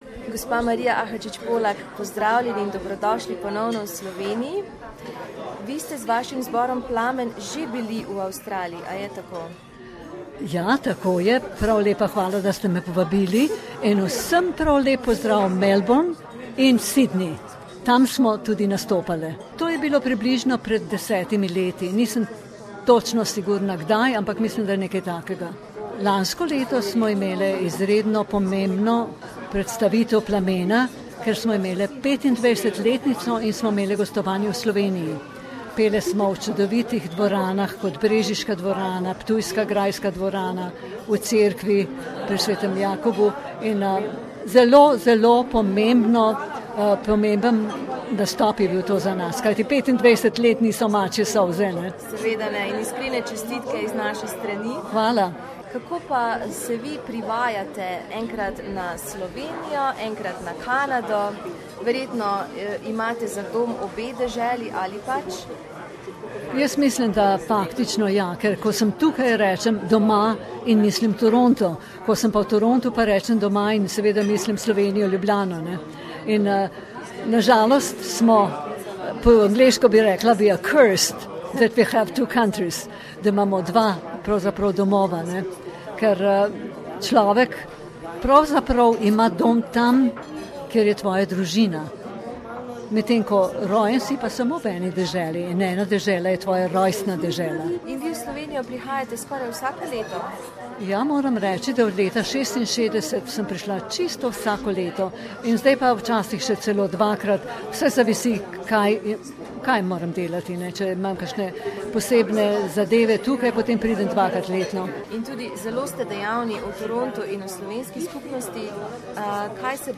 In this interview she talks about her tribute to Slovenia.